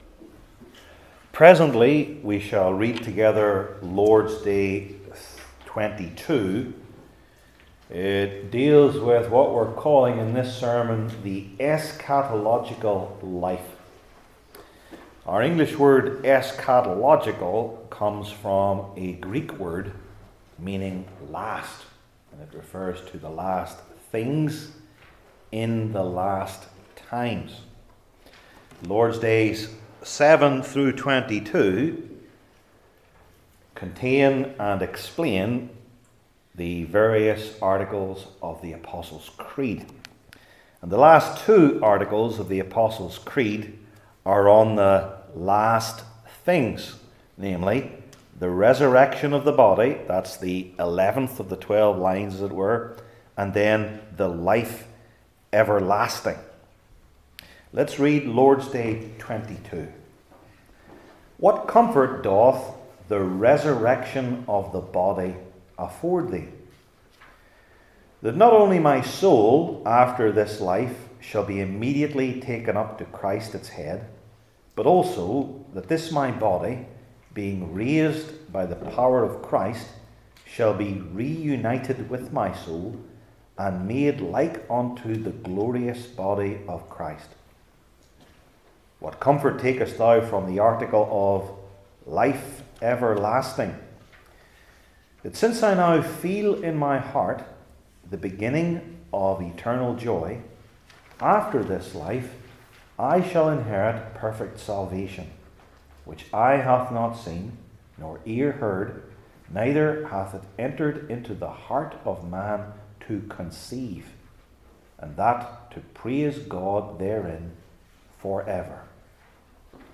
John 11:11-44 Service Type: Heidelberg Catechism Sermons I. After the Bodily Resurrection II.